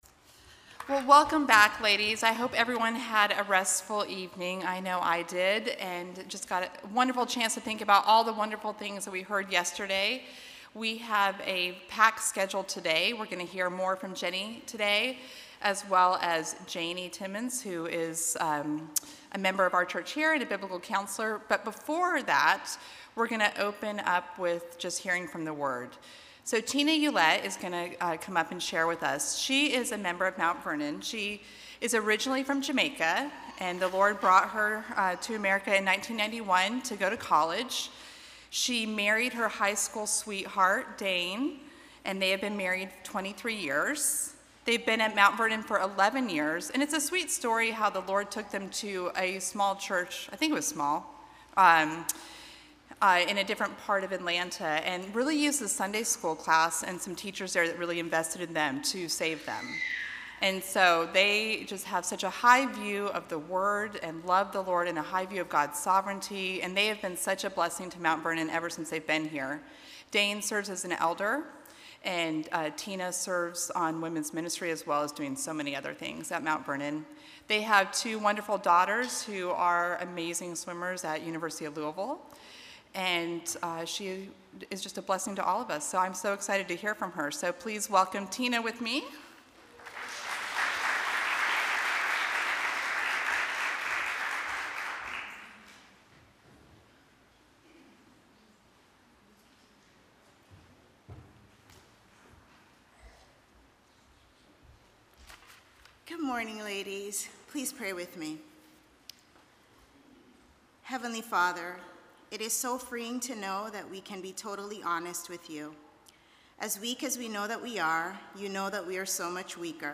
Audio recorded at Feed My Sheep for Pastors Wives Conference 2022.